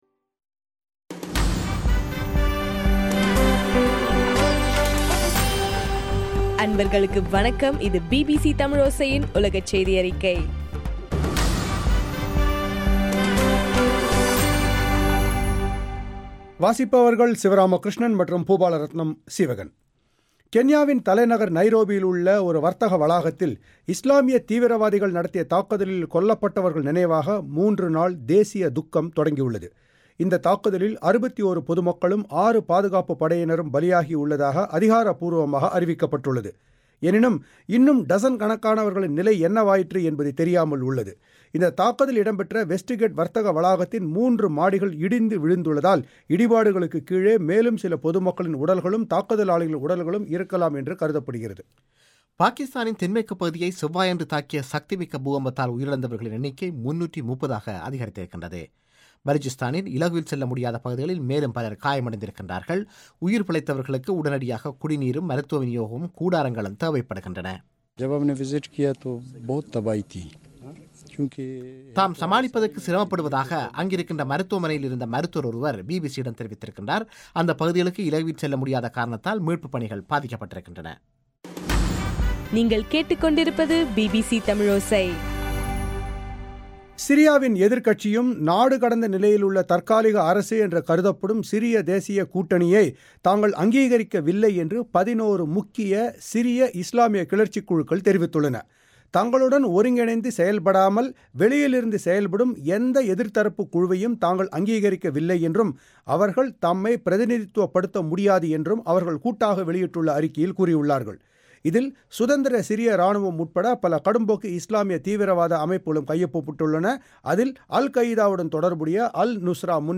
செப்டம்பர் 25 2013 பிபிசி தமிழோசையின் உலகச் செய்திகள்